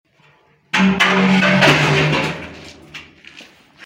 Sound Effects
Loud Object Falling Noise